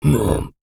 Male_Grunt_Hit_12.wav